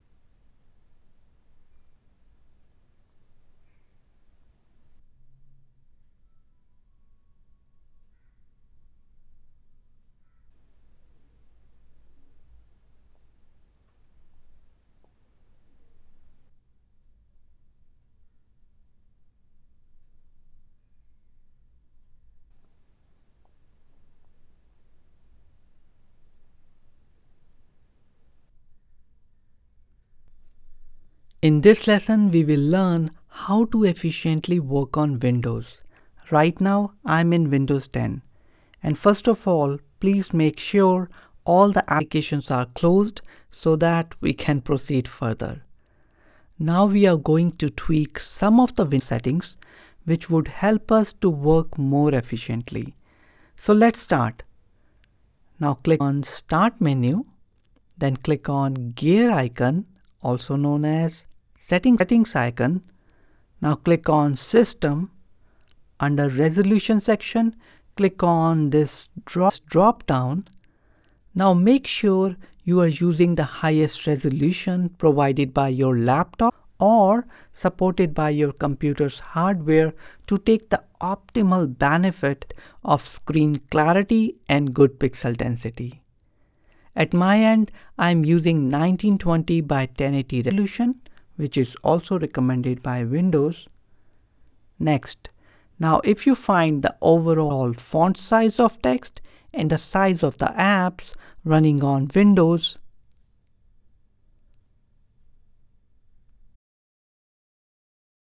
I can definitely hear the noise.
The whine is about 1530hZ, and you can see the noise peak when you run the noise reduction function.
I was happy to see the lowest noise floor of this mic, outstanding But when I compared it with MXL770 I was literally suprised Both mics sound almost similiar and there is just a slight difference in the sound.